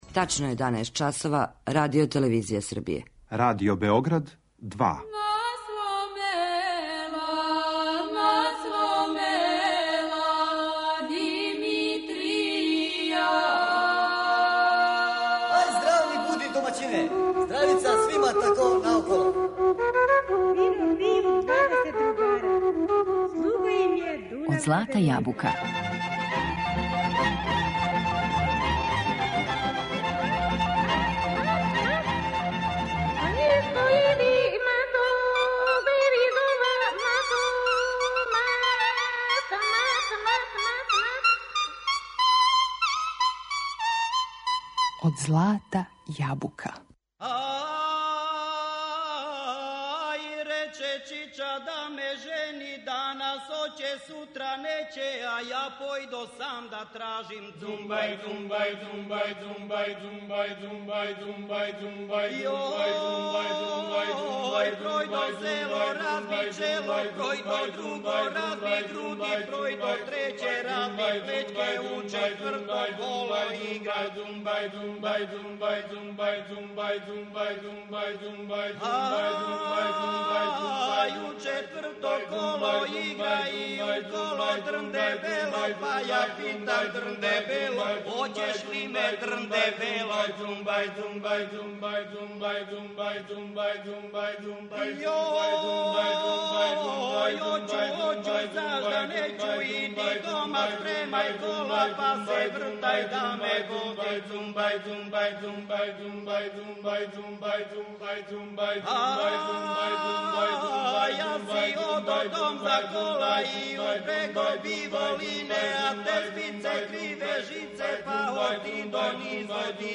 У емисији слушамо теренске музичке снимке који се налазе на компакт-диску који прати ово издање.